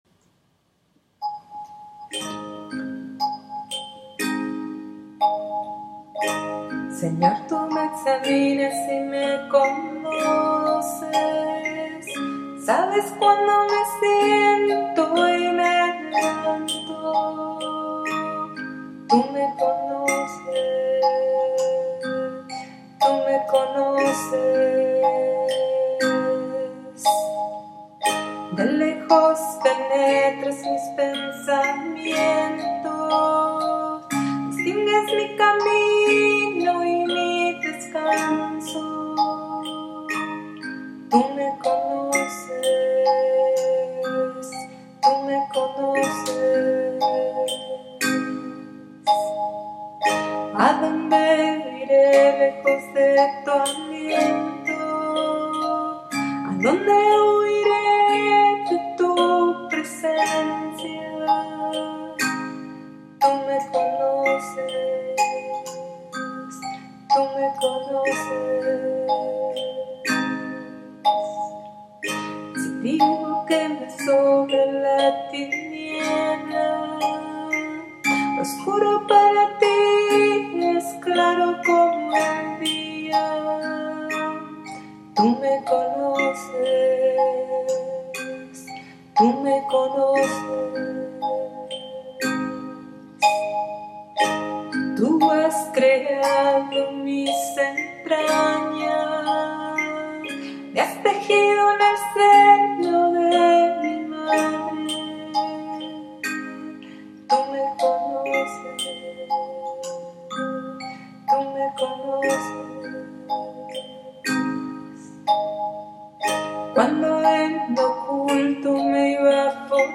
CANCION